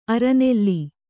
♪ aranelli